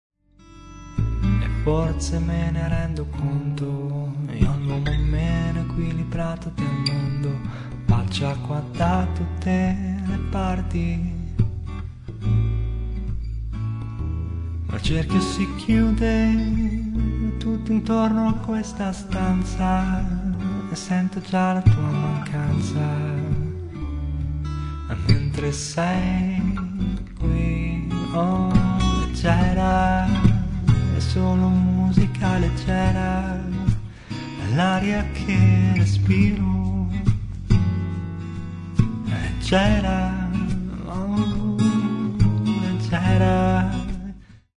abbiamo montato un paio di microfoni
registrato e missato a 'la oficina' studio (bologna)
contrabbasso